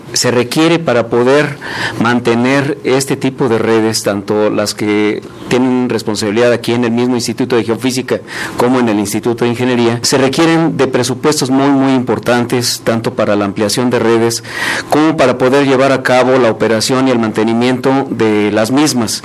en la conferencia de medios.